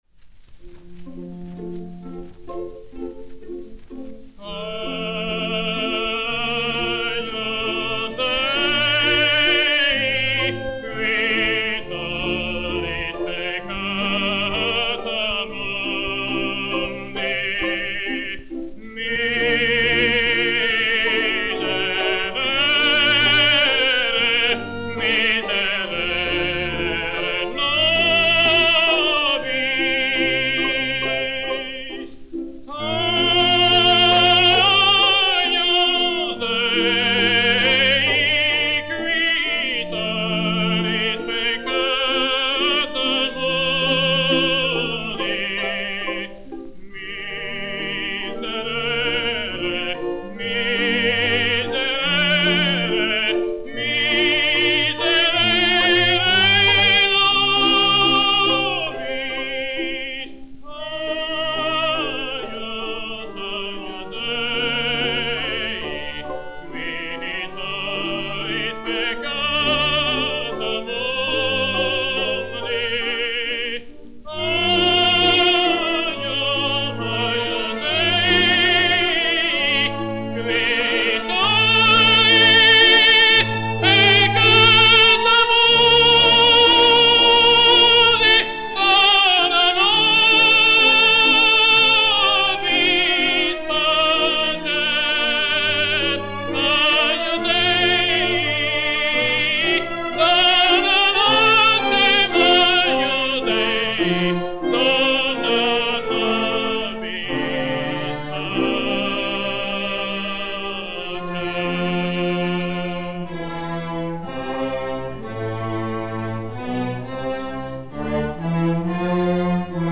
Mélodie religieuse sur la musique de l'Arlésienne de Georges BIZET (1872).
ténor